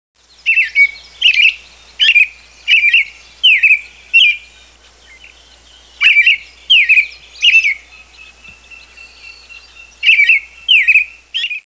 chant d'un merle pour tester ces 3 fonctionnalités.
merle-amerique_01.wav